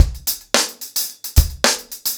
TupidCow-110BPM.5.wav